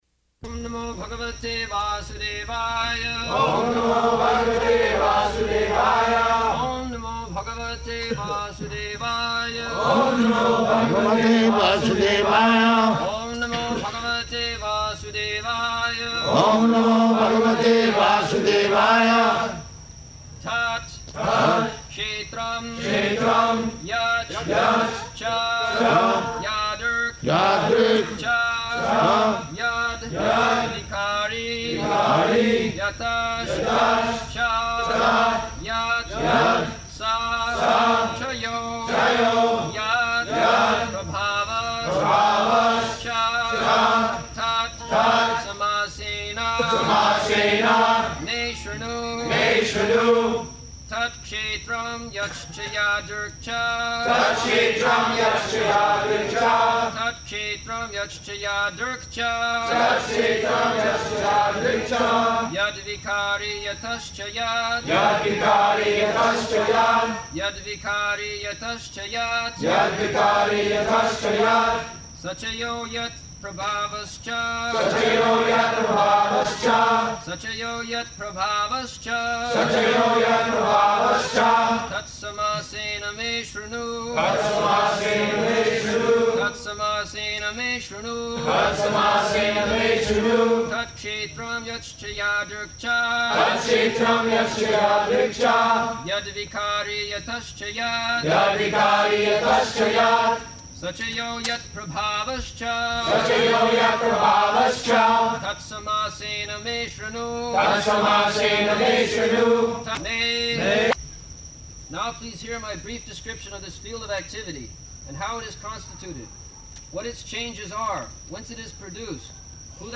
February 27th 1975 Location: Miami Audio file
[Prabhupāda and devotees repeat] [leads chanting of verse, etc.]